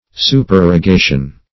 Supererogation \Su`per*er`o*ga"tion\, n. [L. supererogatio a